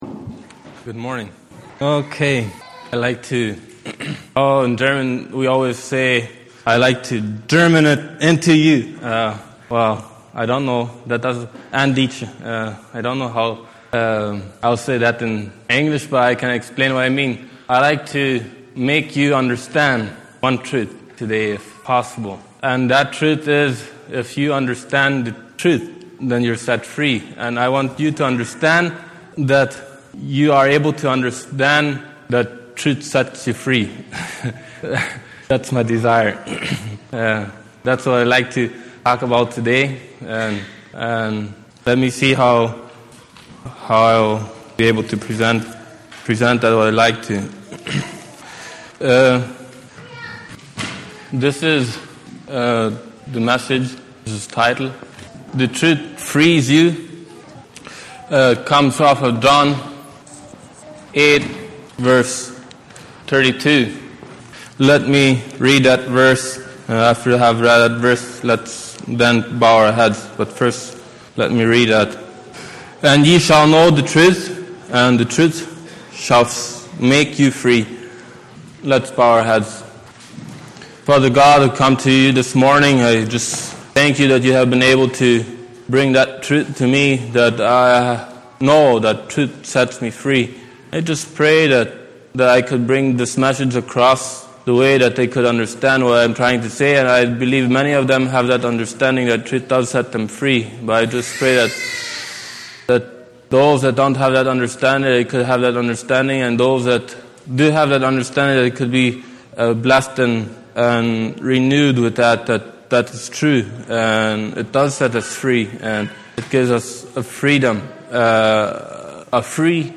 Sunday Morning Sermon Passage